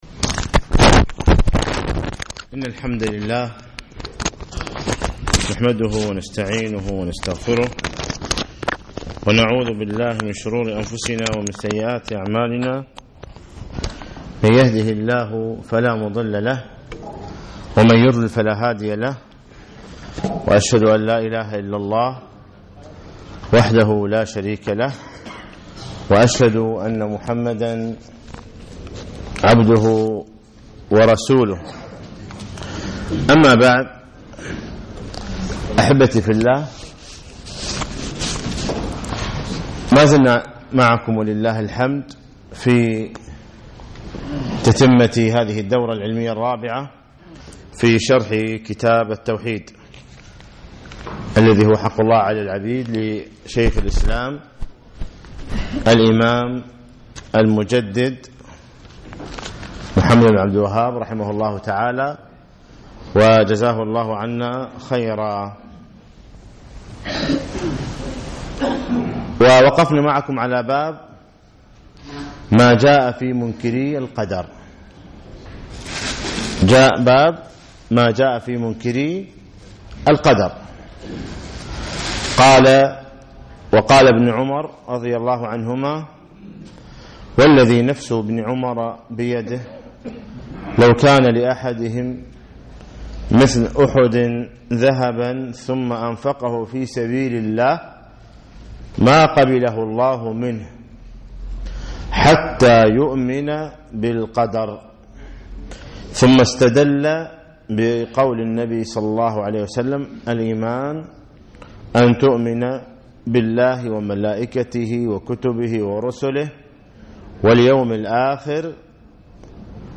الدرس الثالث والثلاثون